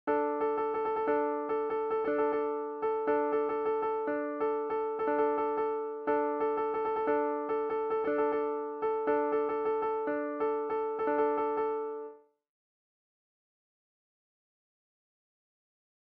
565. Throw and Scale patt over Differ Durs, Rit